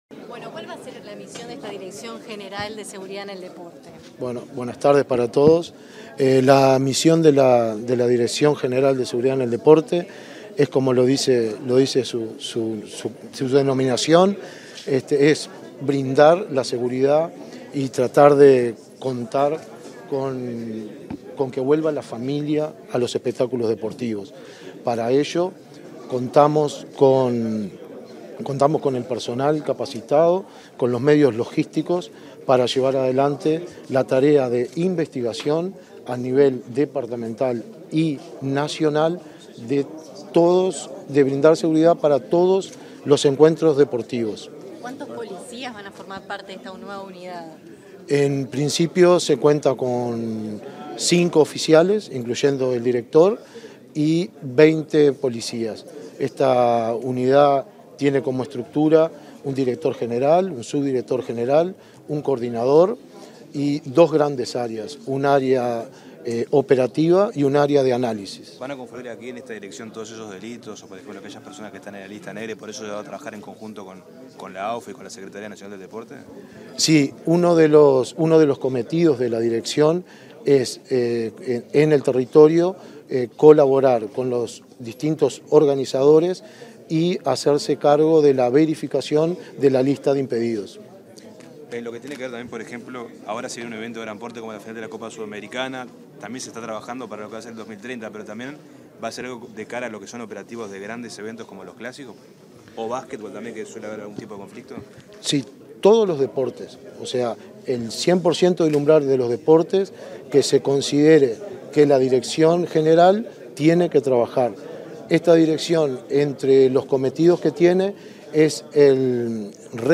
Declaraciones del director de Seguridad en el Deporte, Álvaro García
Declaraciones del director de Seguridad en el Deporte, Álvaro García 23/10/2023 Compartir Facebook X Copiar enlace WhatsApp LinkedIn Tras la presentación de la Dirección de Seguridad en el Deporte, este 23 de octubre, el nuevo titular comisario mayor Álvaro García, realizó declaraciones a la prensa.